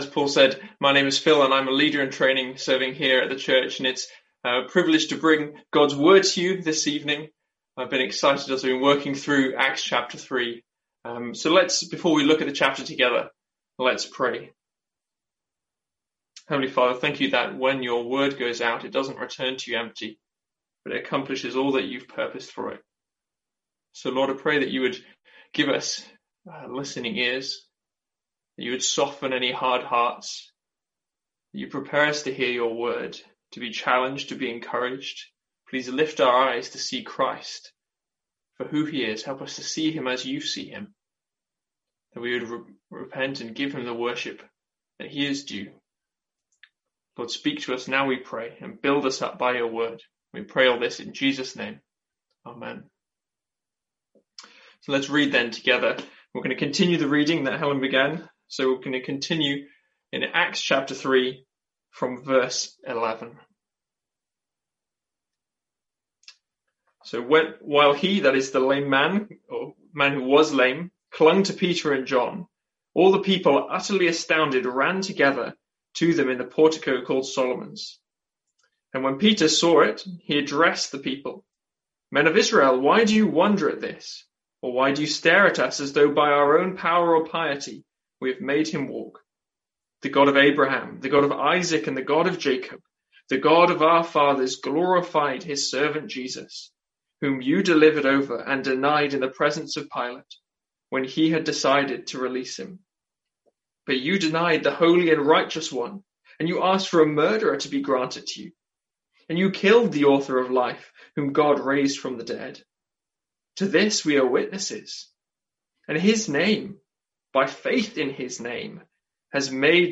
Sermons | St Andrews Free Church
From our evening service in Acts.